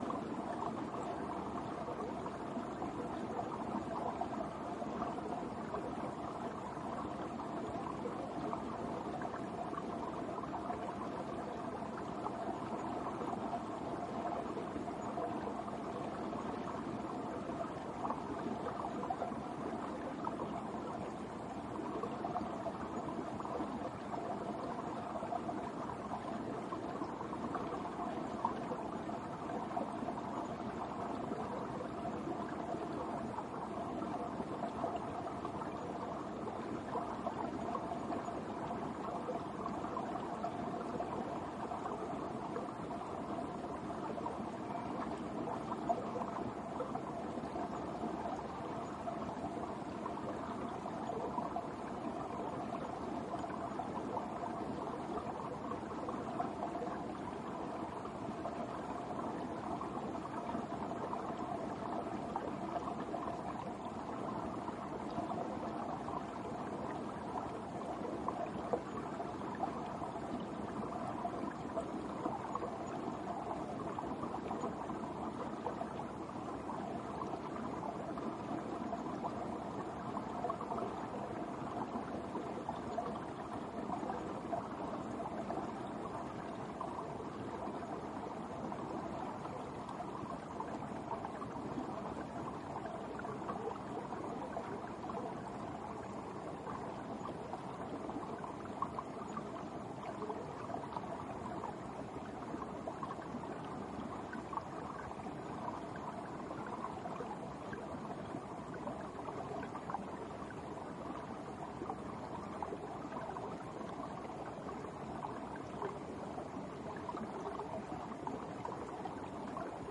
水 " 小溪 冬季冰林 冰下水 1
描述：在森林里记录一条小溪。水在薄薄的冰层下面流动，使其具有过滤效果。用H2N变焦记录仪记录。
Tag: 冬季 森林 小溪 小溪 溪流 现场记录